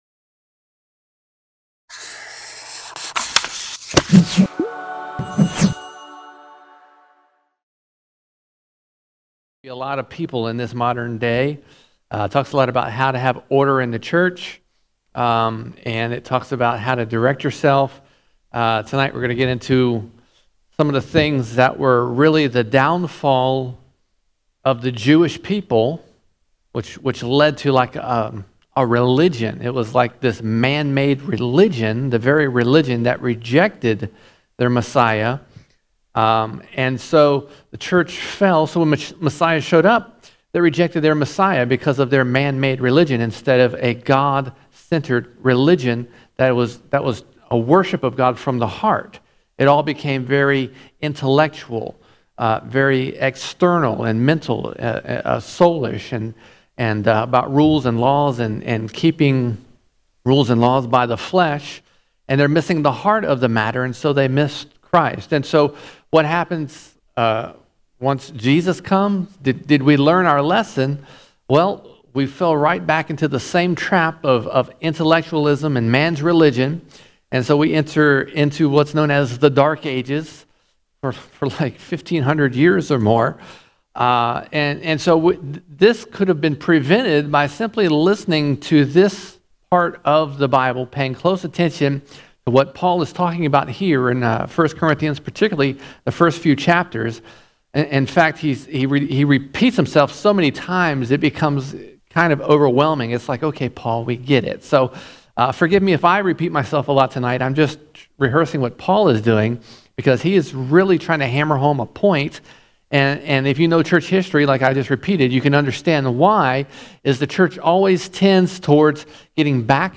15 October 2020 Series: 1 Corinthians All Sermons 1 Corinthians 1:18 to 2:2 1 Corinthians 1:18 to 2:2 The Gospel is only for those willing to humble themselves and believe by faith.